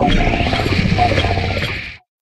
Cri de Mite-de-Fer dans Pokémon HOME.